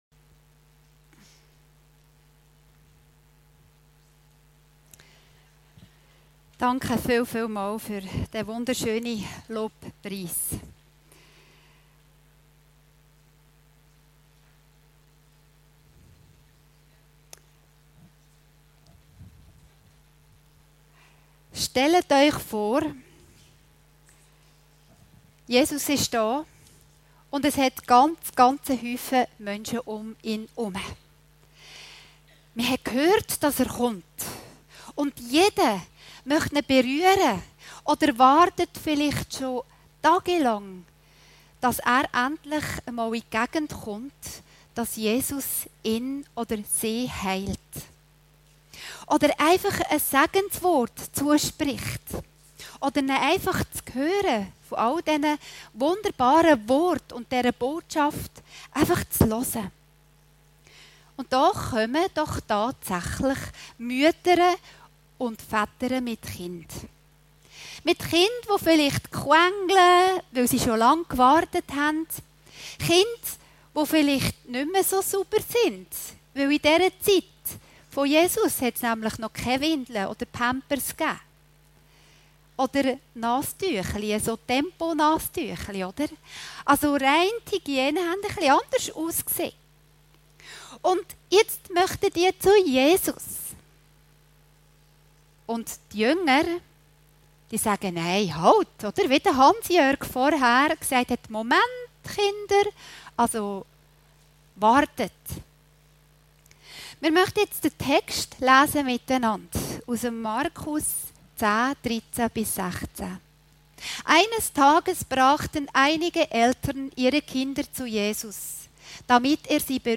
Predigten Heilsarmee Aargau Süd – lasset-die-kinder-zu-mir-kommen